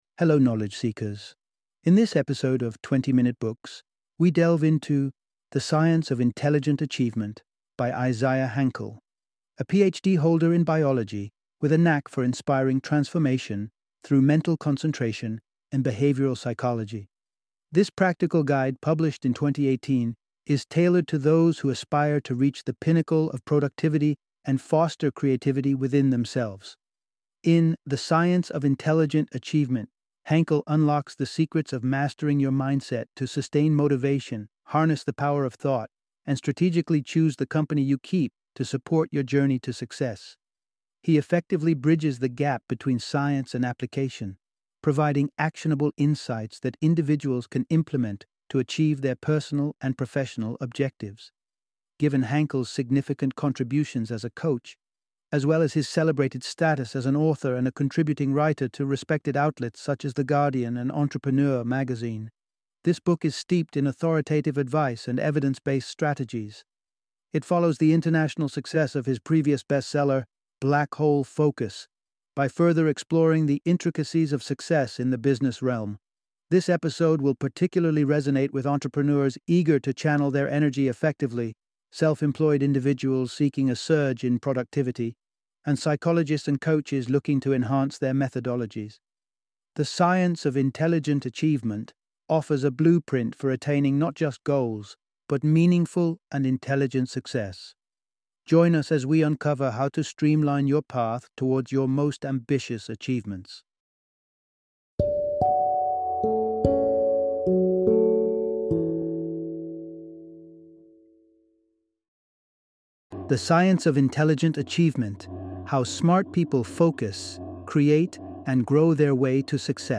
The Science of Intelligent Achievement - Audiobook Summary